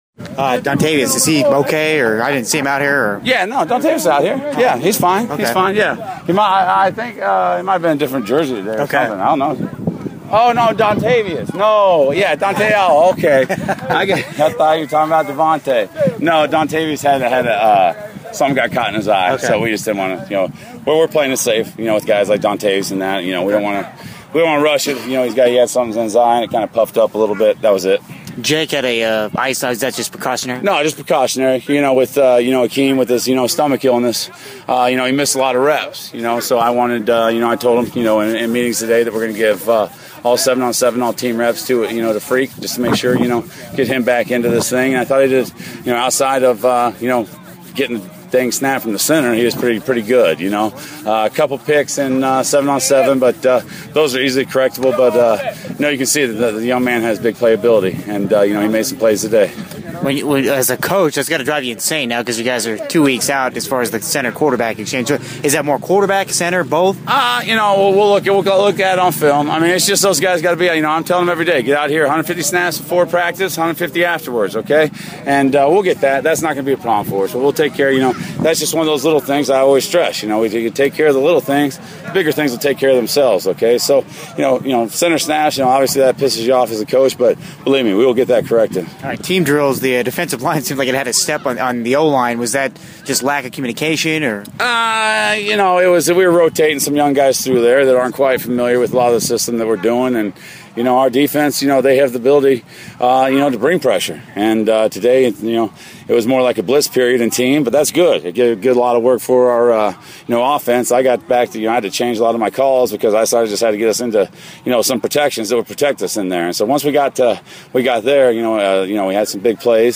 Saturday’s Interviews